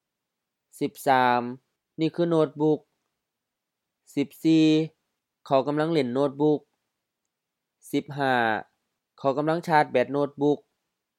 โน่ดบุ่ก no:t-buk H-H โน้ตบุ๊ก notebook, laptop
ซ่าด sa:t H ชาร์จ to charge (a battery, a device)